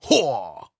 Effort Sounds
17. Effort Grunt (Male).wav